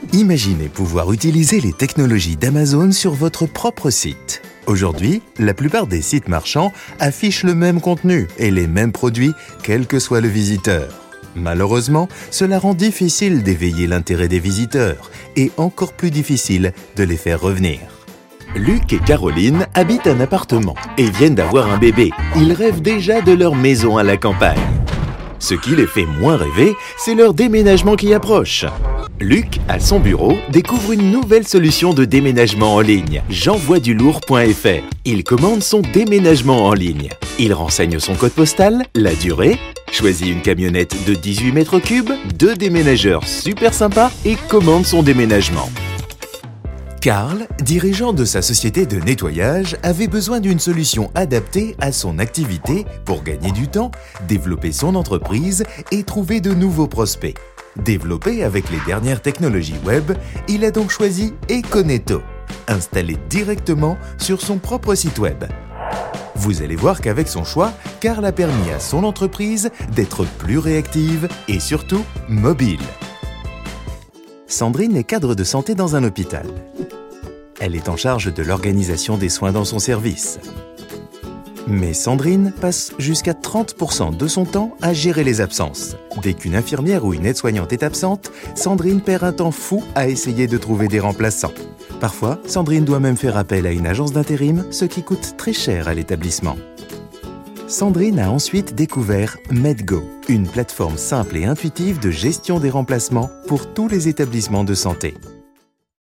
Male
20s, 30s, 40s, 50s
Corporate, Friendly, Natural
parisian , west african
Main reel.mp3
Microphone: Neumann U87 , Sennheiser MKH416
Audio equipment: Universal Audio Apollo , Auralex Booth